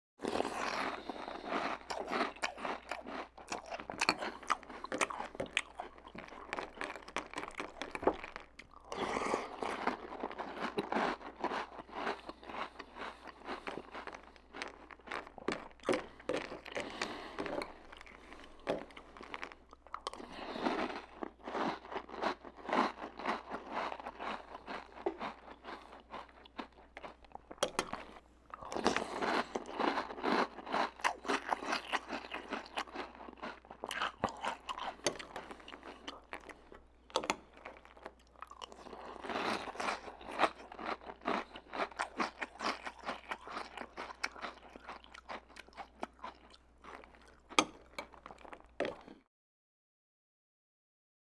Eating Cereal Close Up Sound
human
Eating Cereal Close Up